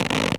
foley_leather_stretch_couch_chair_03.wav